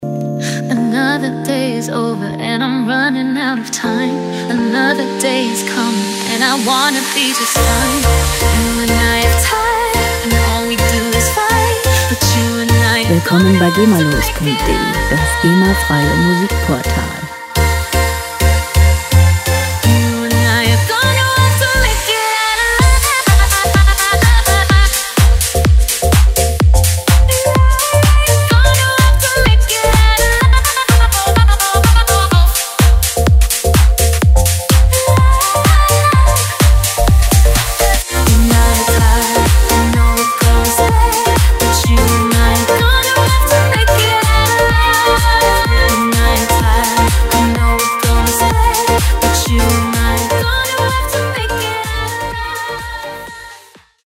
• Vocal House